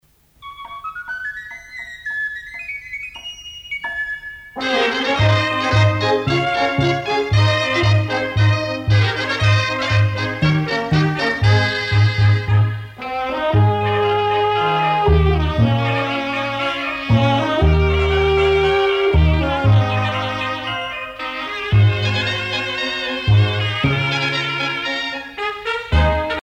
danse : sardane
Pièce musicale éditée